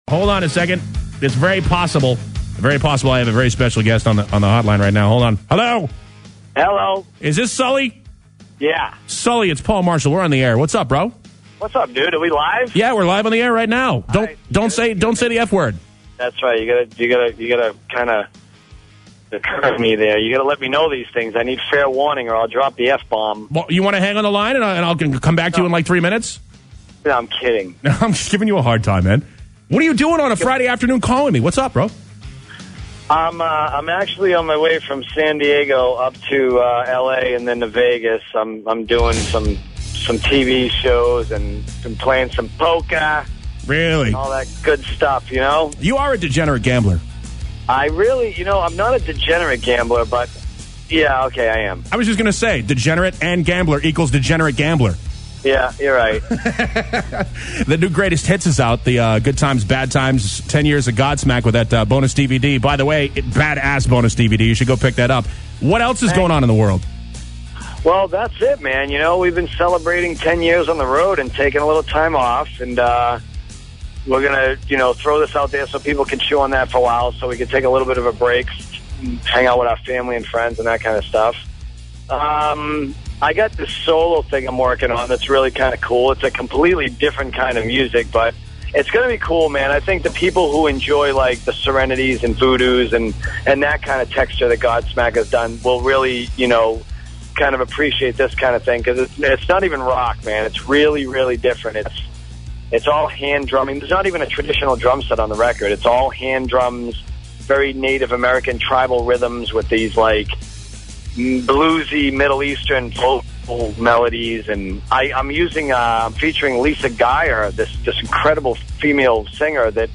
But here…you get the unedited feed.